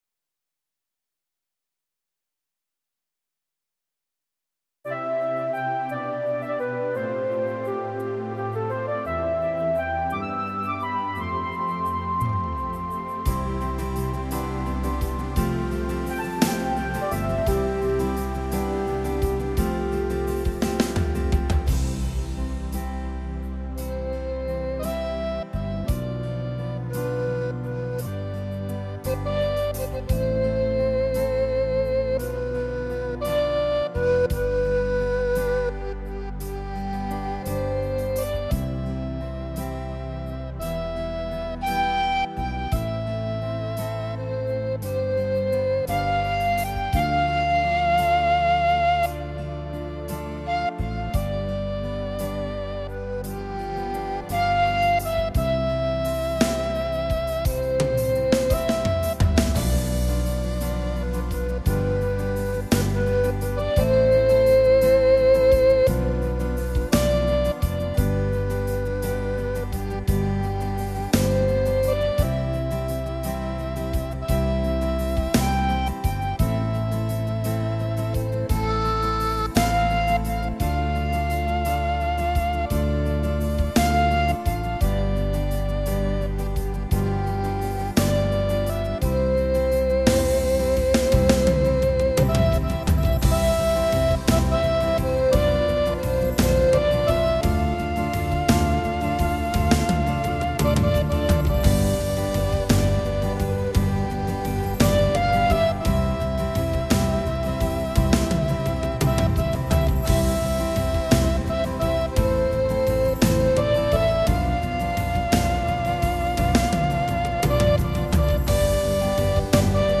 Arranged & keyboard